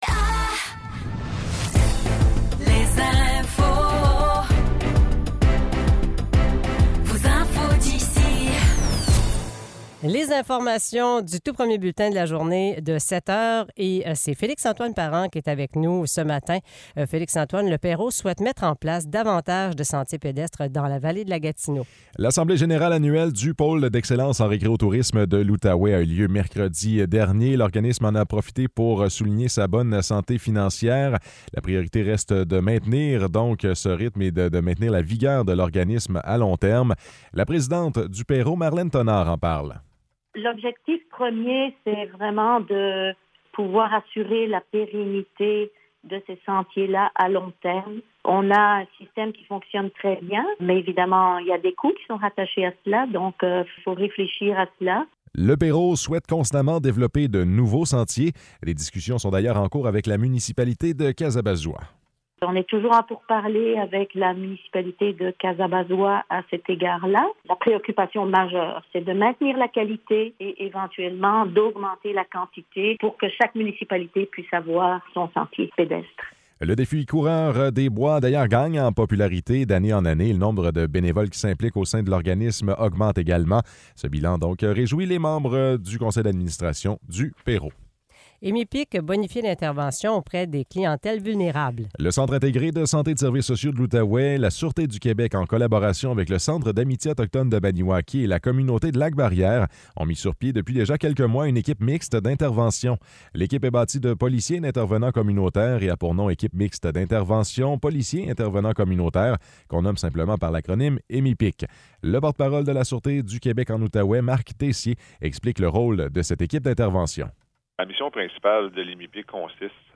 Nouvelles locales - 8 novembre 2023 - 7 h